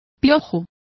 Complete with pronunciation of the translation of louse.